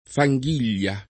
fanghiglia [ fa jg& l’l’a ] s. f.